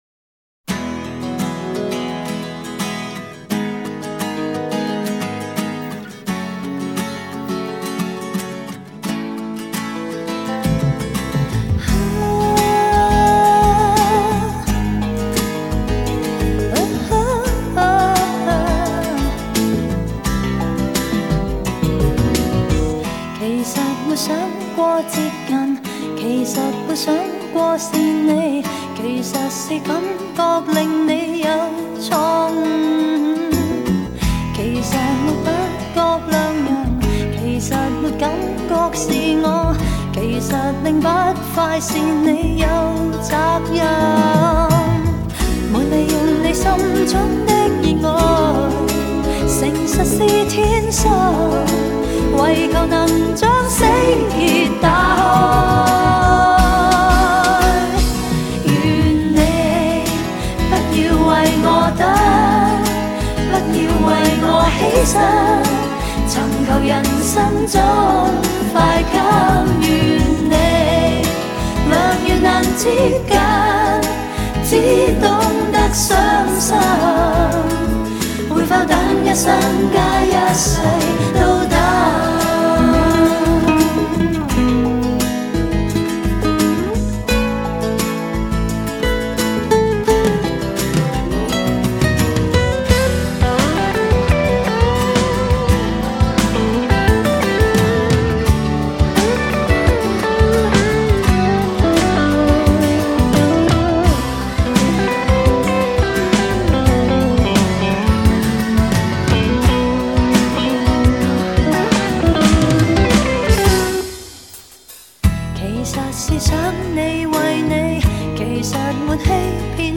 嗓音很出色 也有点另类